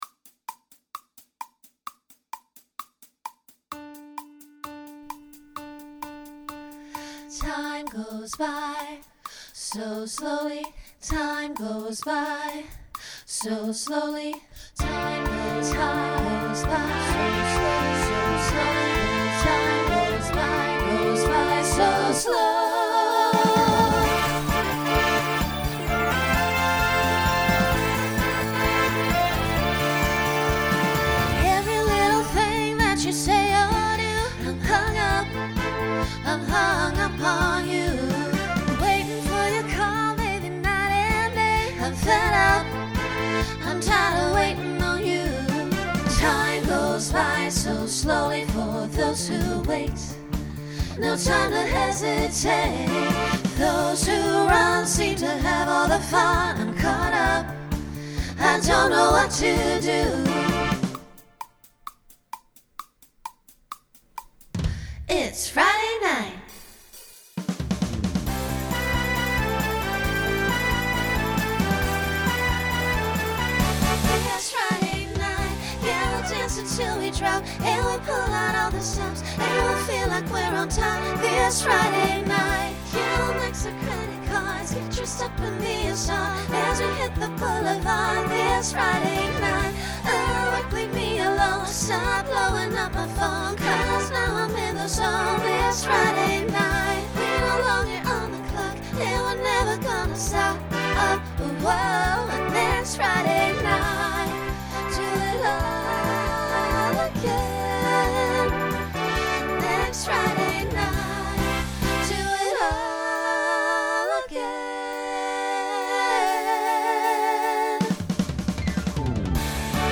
Genre Country , Pop/Dance Instrumental combo
Voicing SSA